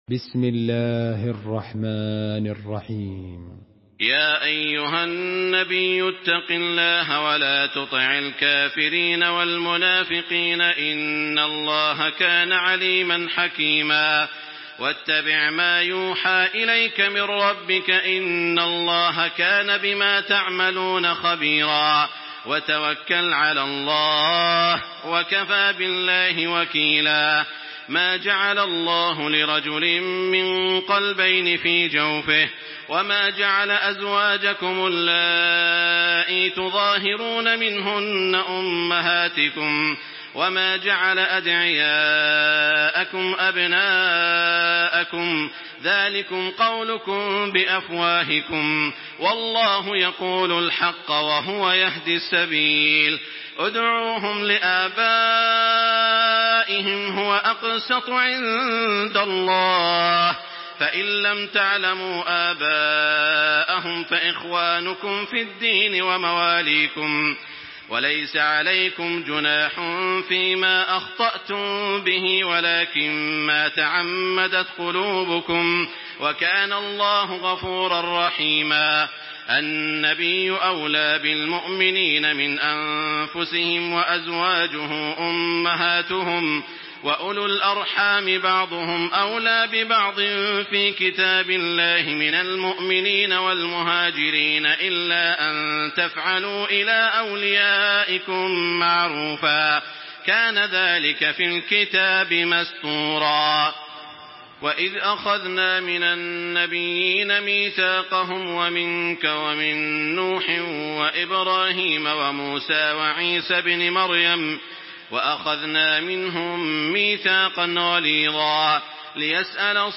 سورة الأحزاب MP3 بصوت تراويح الحرم المكي 1426 برواية حفص
تحميل سورة الأحزاب بصوت تراويح الحرم المكي 1426